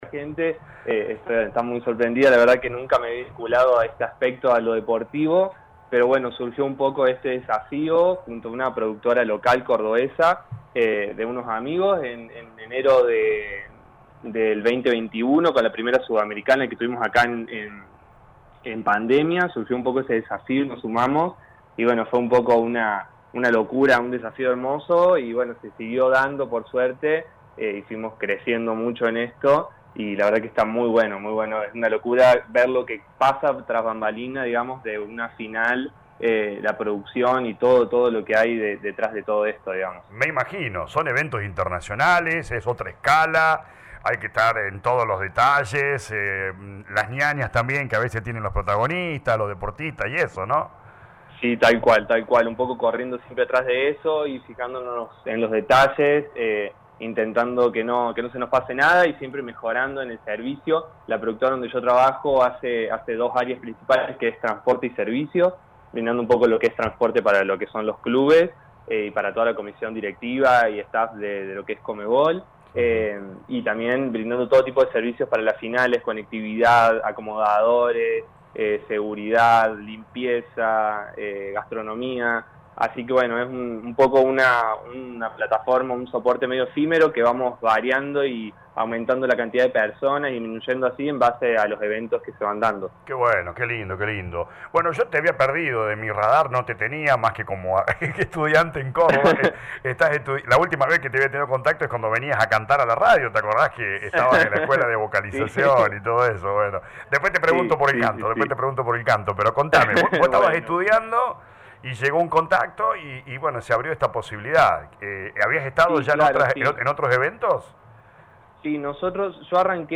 dialogó con LA RADIO 102.9 FM